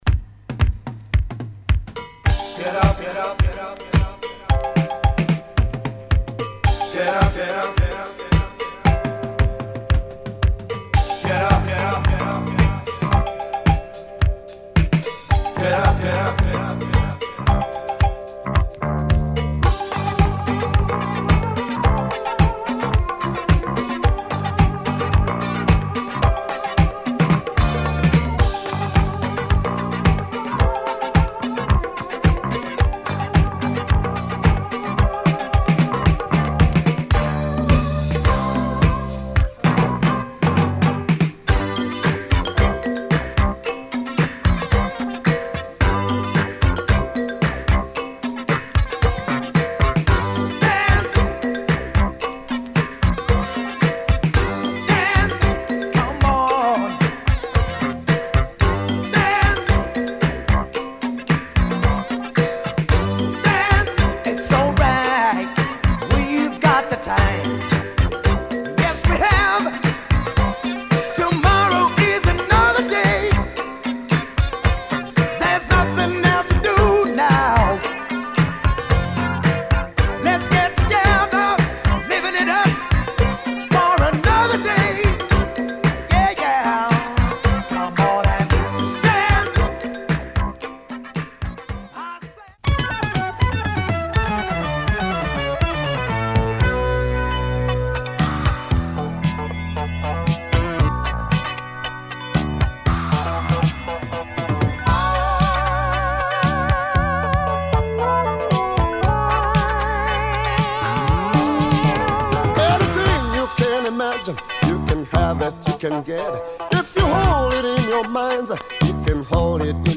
South African disco funk